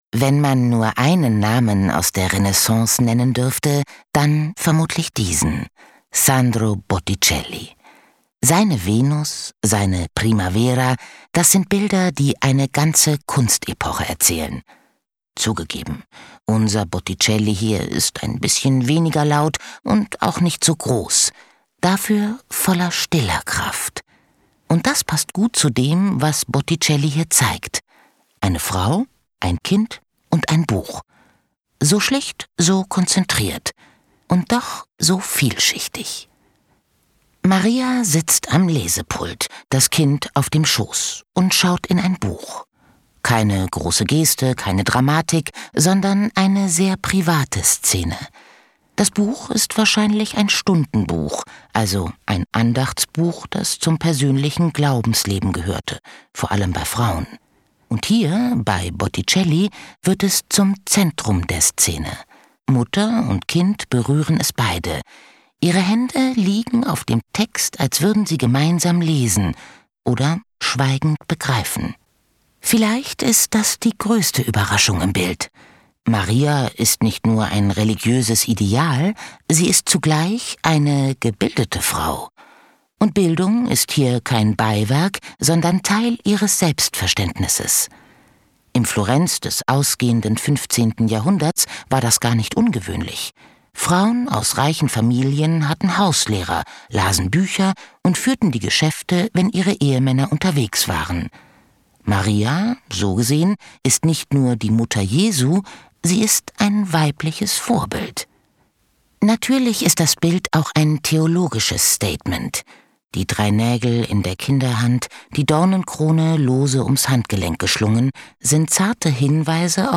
Ausstellung »GÖTTLICH!« im DIMU Freising